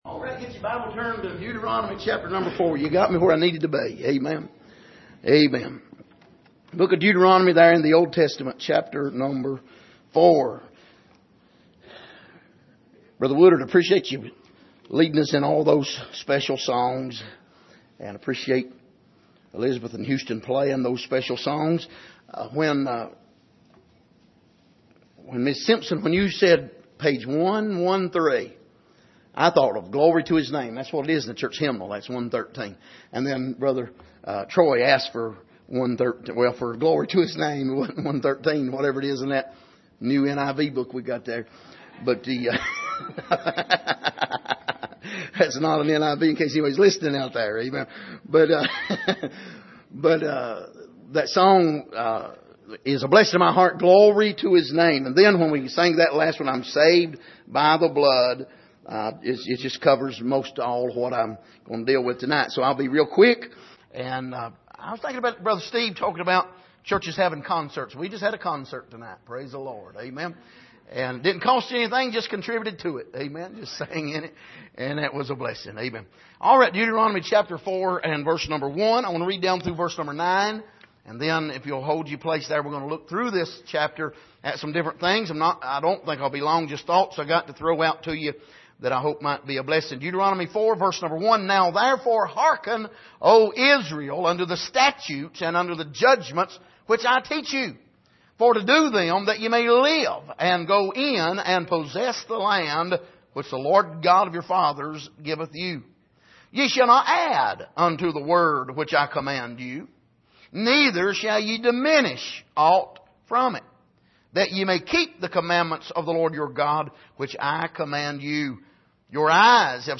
Passage: Deuteronomy 4:1-9 Service: Sunday Evening